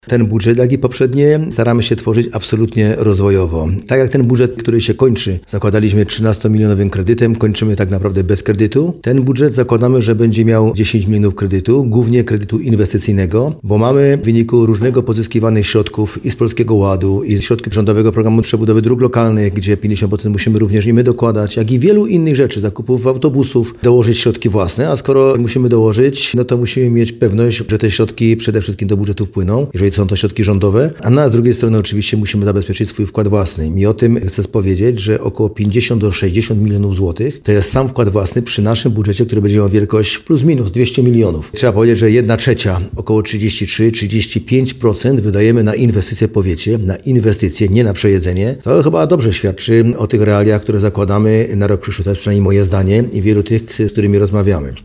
– Ten budżet, tak jak poprzednie, staraliśmy się tworzyć absolutnie rozwojowo – mówił Andrzej Płonka na naszej antenie.